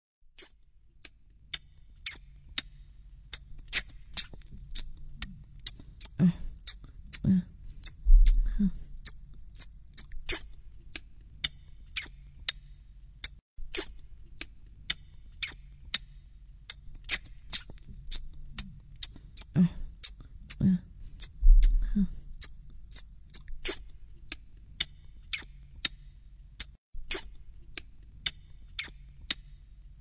The tune is again about kissing. A good sound effect of kissing.